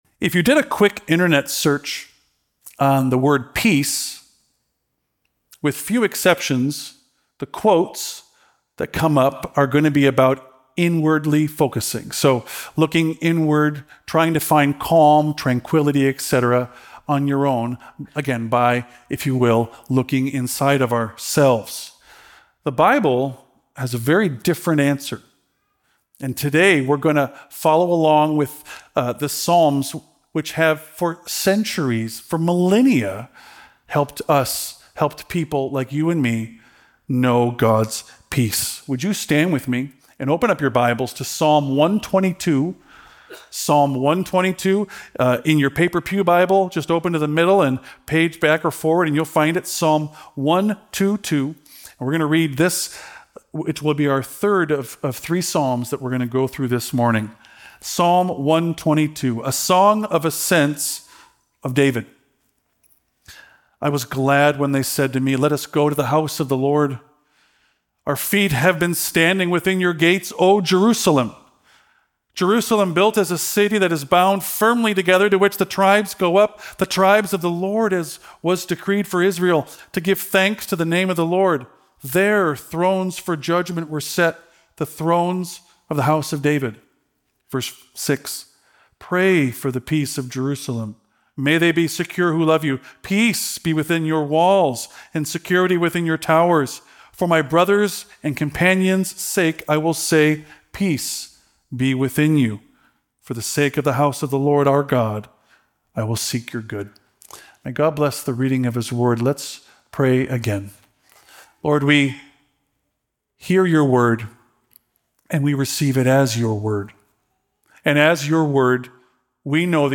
Palm Sunday Sermon